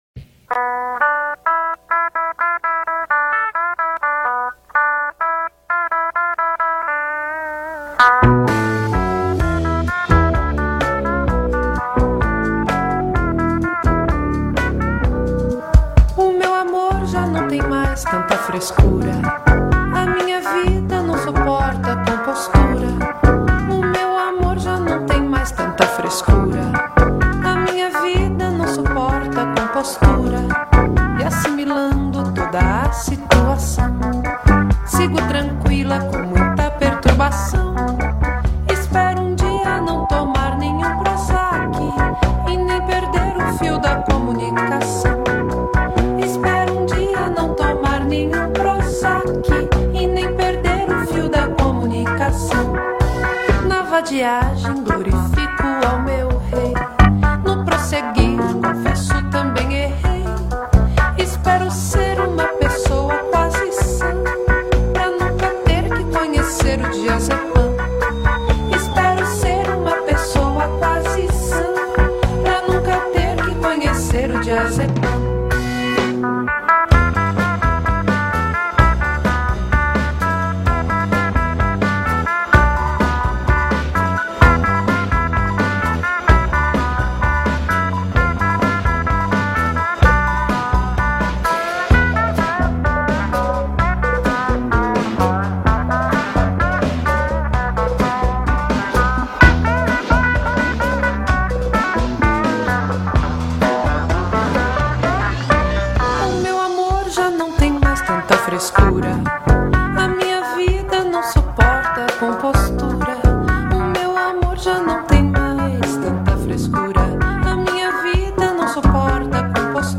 baixo
bateria
guitarra